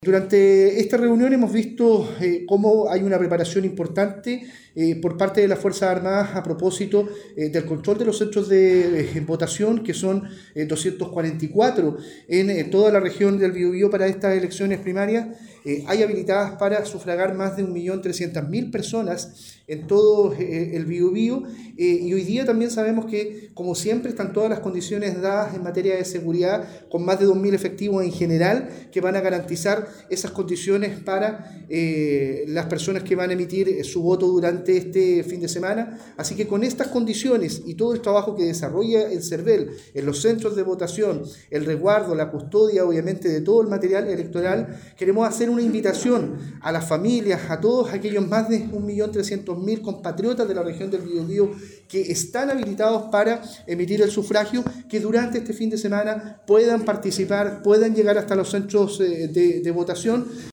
“Hemos tenido una reunión, tanto con las Fuerzas Armadas, Fuerzas de Orden y Seguridad y también las distintas instituciones del Estado, encabezadas por el Servel, que hacen posible que los procesos electorales funcionen como se acostumbra en nuestro sistema democráticos: a la perfección y con un despliegue muy importante del Estado, para que ciudadanos y ciudadanas puedan asistir a los centros de votación con total tranquilidad y emitir su sufragio para manifestar su opinión ciudadana”, dijo el delegado.